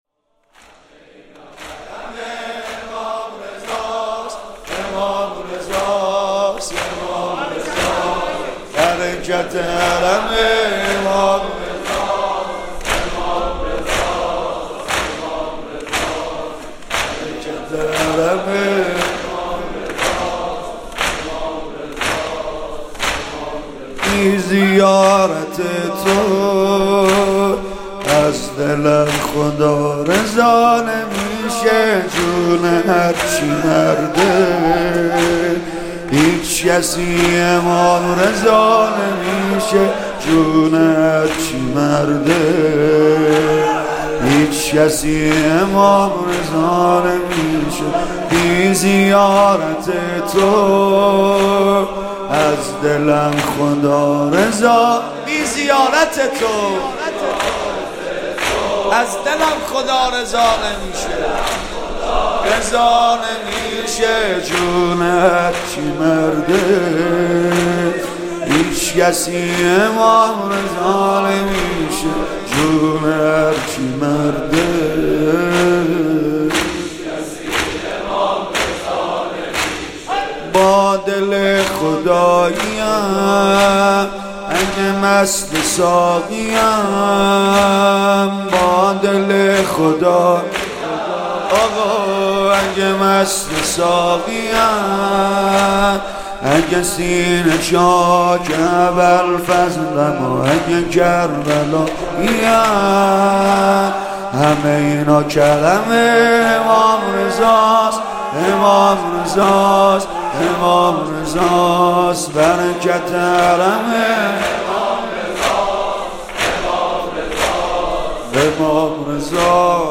مداحی سیدرضا نریمانی / همه اینا کرم امام رضاست